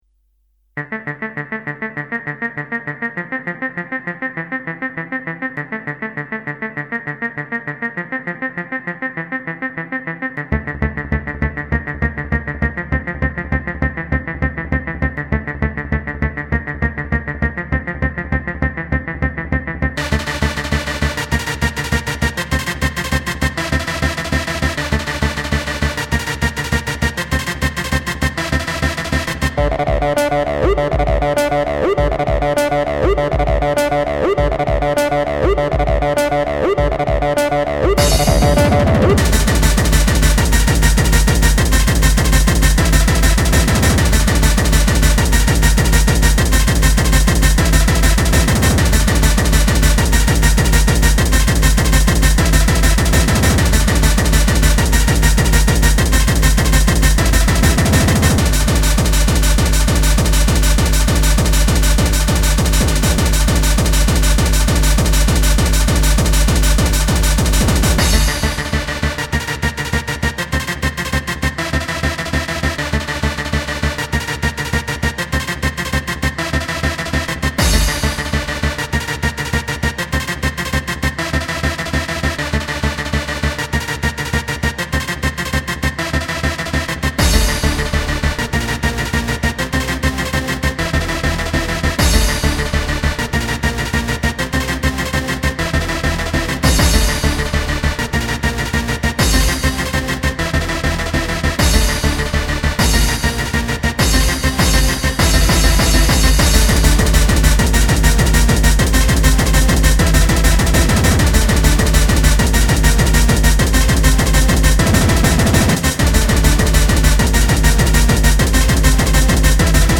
hardcore mix
• Quality: 44kHz, Stereo